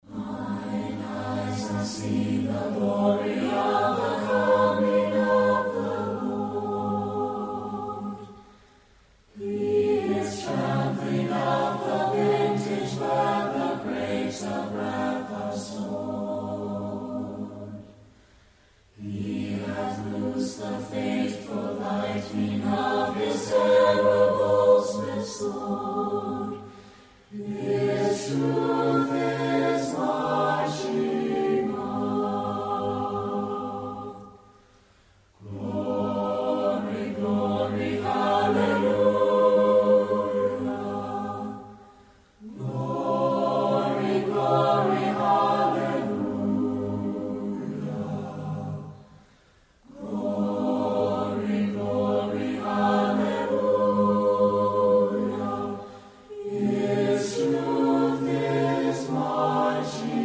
Performers: U.S. Air Force Chorale